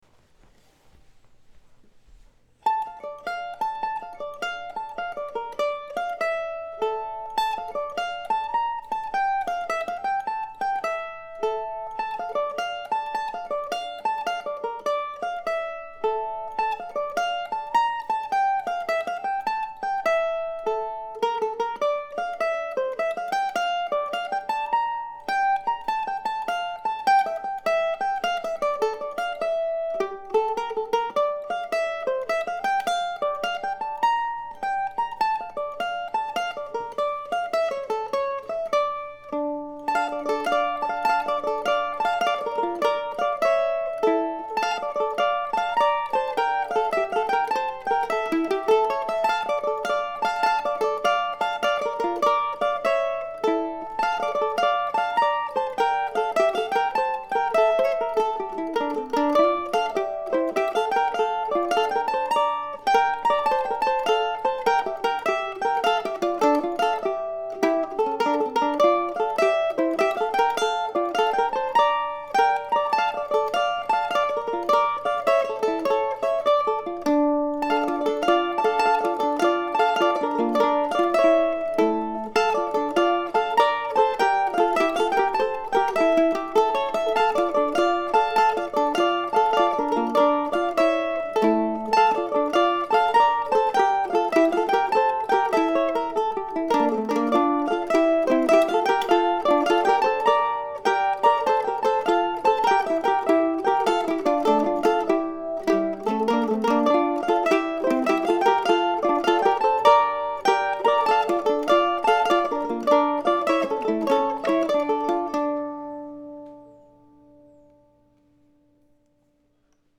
The tune itself offers an opportunity to practice some arpeggios in the key of D minor and is fun to play.